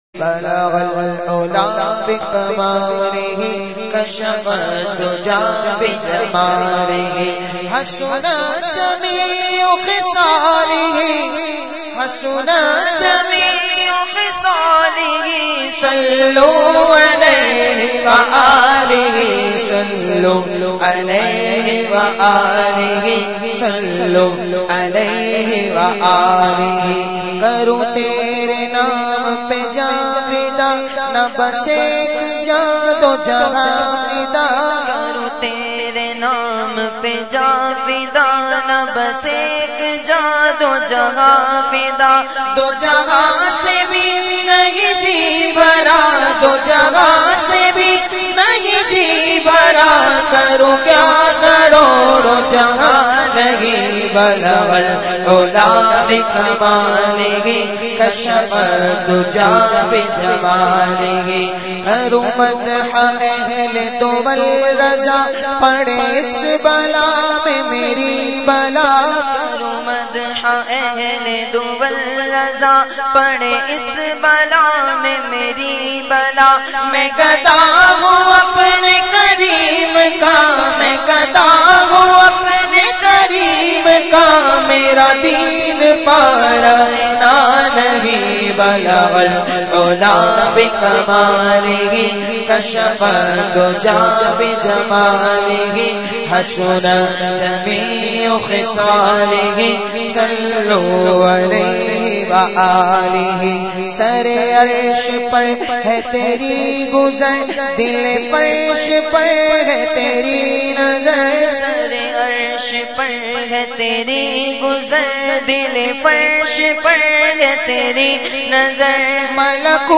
Naat Sharif
recited by famous Naat Khawan of Pakistan